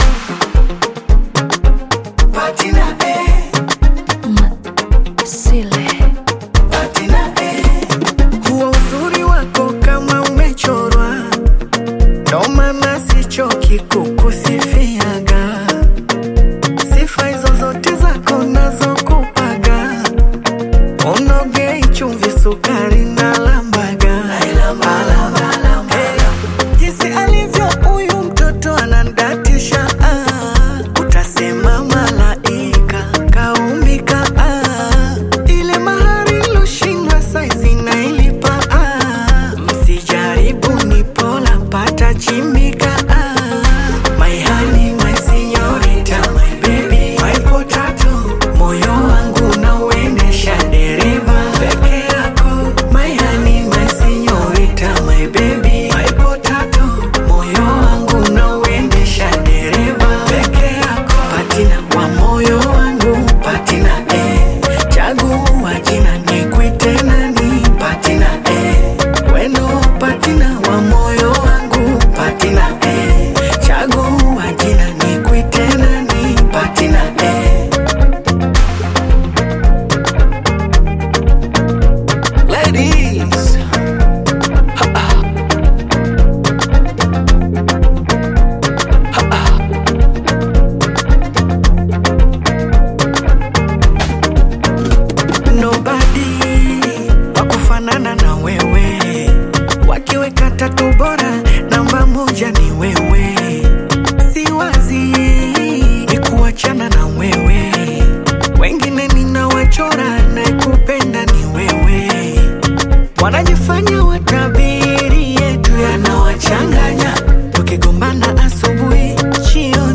Bongo Flava
Tanzanian Bongo Flava artist, singer, and songwriter